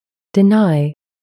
程序员容易发音错误的单词（点击🔊收听正确读音）
deny 🔊 /dɪ'naɪ/